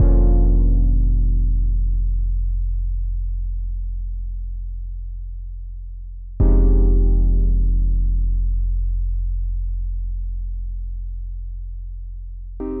Tag: 75 bpm Hip Hop Loops Piano Loops 2.15 MB wav Key : F